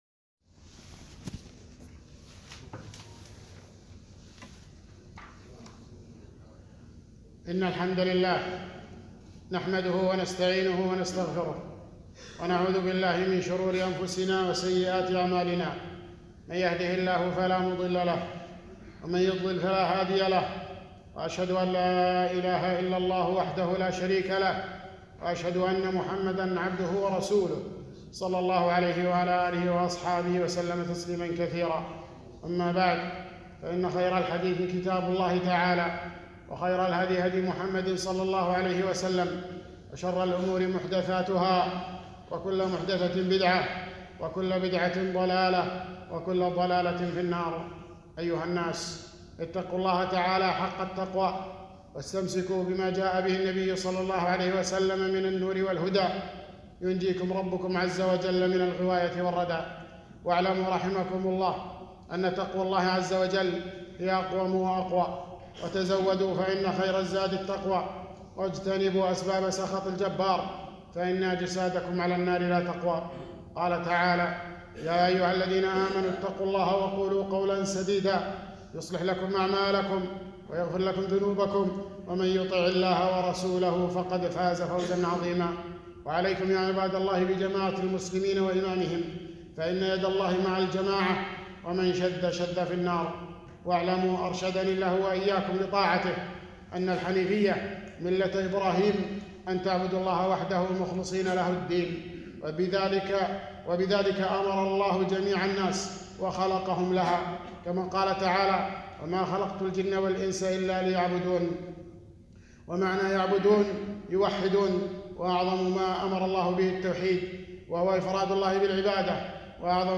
خطبة - الأصول الثلاثة- مختصر رسالة الإمام محمد بن عبدالوهاب